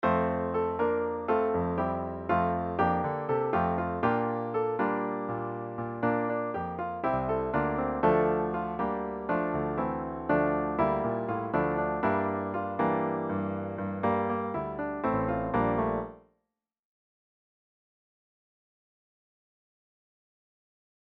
2) Example 2 is the 8-bar melody, with the first 4 bars in F major, and the next 4 bars transposed into D minor (the relative minor). This kind of relative minor transposition results in a smoother transition, quite useful in many song treatments.